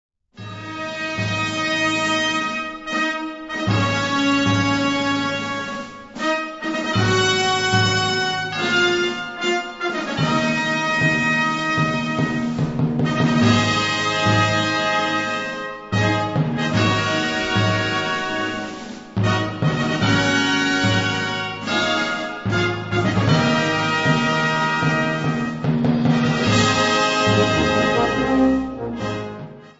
Categoria Concert/wind/brass band
Sottocategoria Musica per concerti
Instrumentation Ha (orchestra di strumenti a faito)